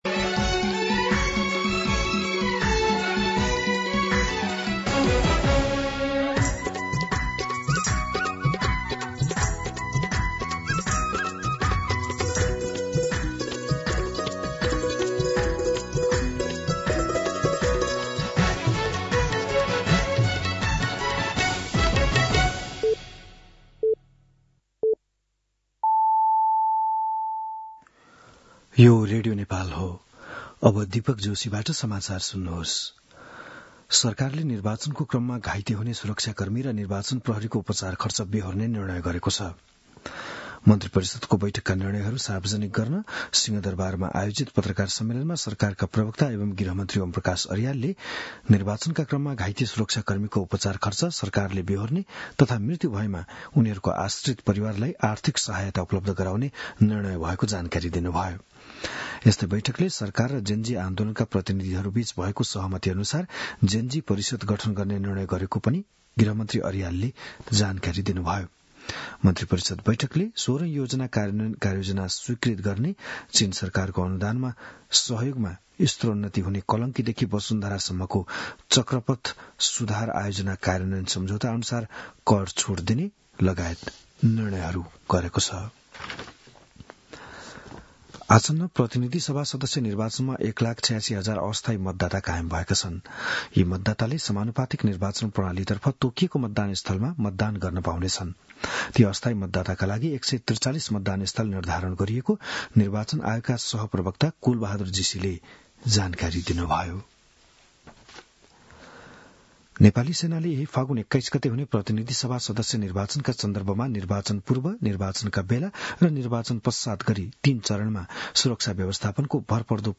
बिहान ११ बजेको नेपाली समाचार : १४ फागुन , २०८२